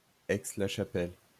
French Aix-la-Chapelle[8] [ɛks la ʃapɛl]